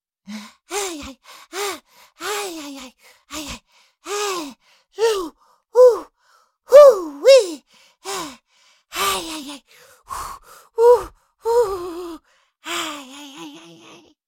Cartoon Little Monster, Voice, Breathing 2 Sound Effect Download | Gfx Sounds
Cartoon-little-monster-voice-breathing-2.mp3